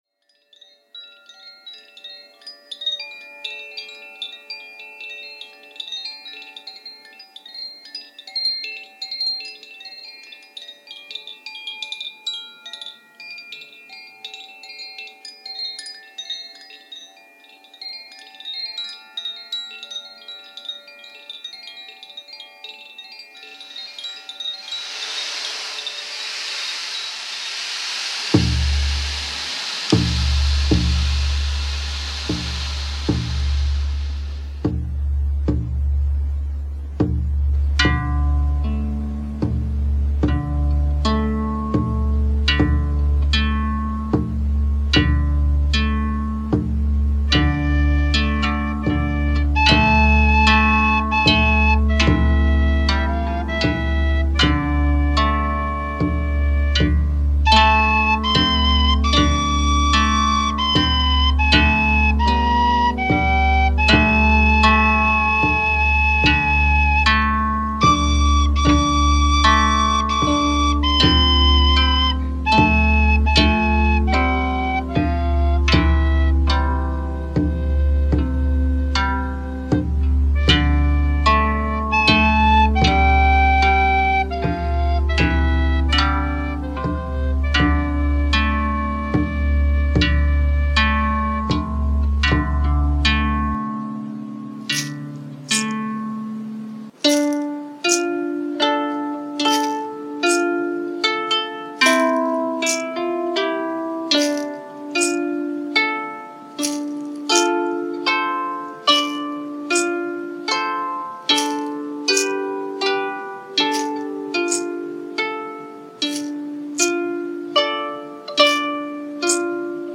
Plus Oriole (soprano) recorder and deerskin frame drum, because why not?
Take a trip to the seaside with me!
scarborough_fair-harp.mp3